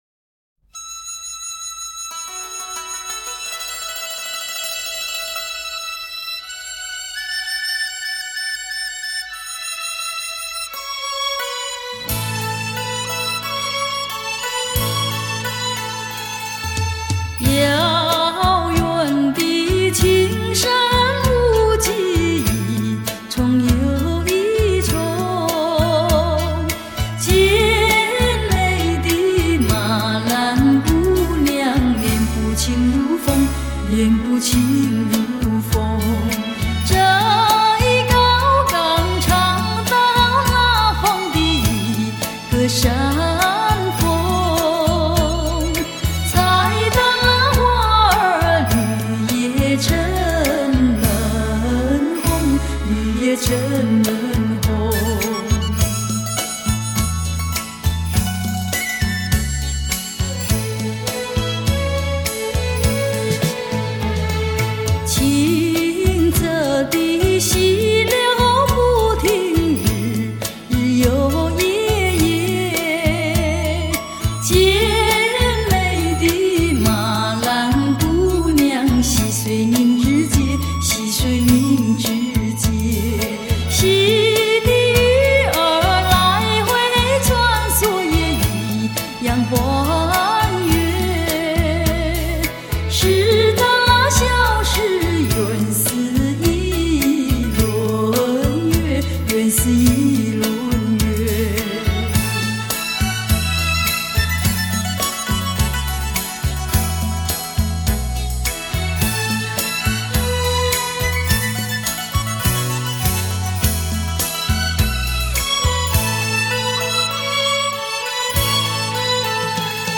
录音室：Oscar Studio S'pore